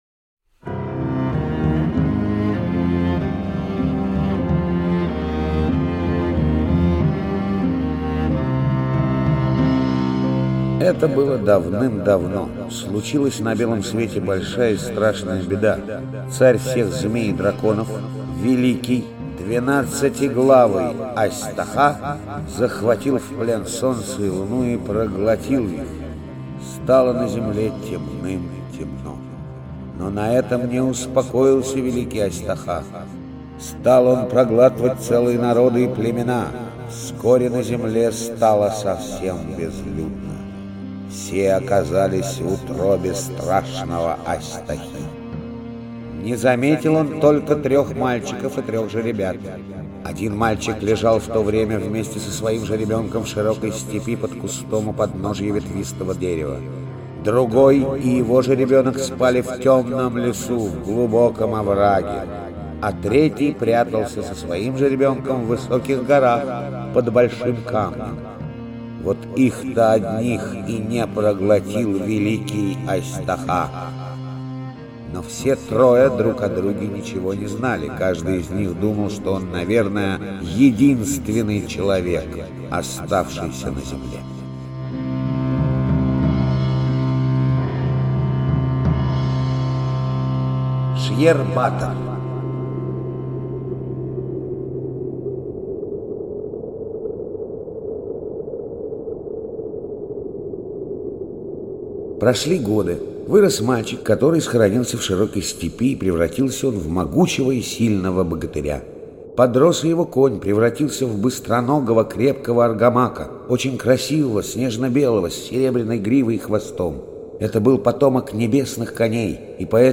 Сьер-батор - чувашская аудиосказка - слушать онлайн